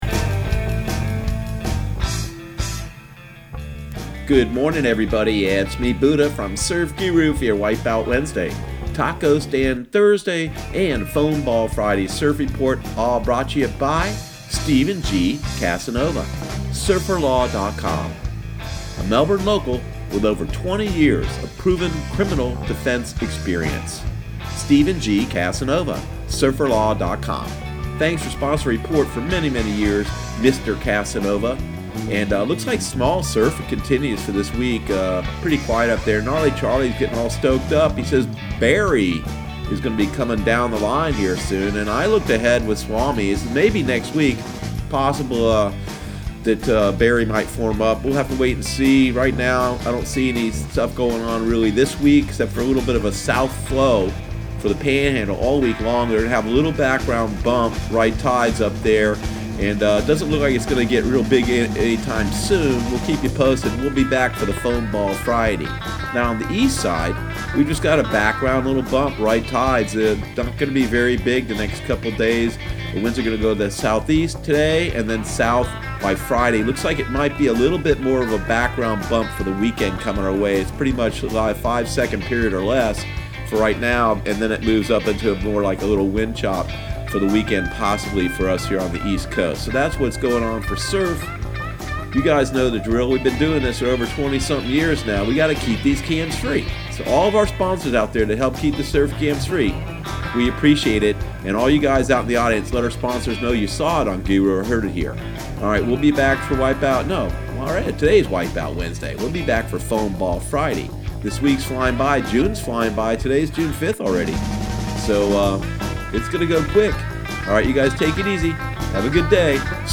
Surf Guru Surf Report and Forecast 06/05/2019 Audio surf report and surf forecast on June 05 for Central Florida and the Southeast.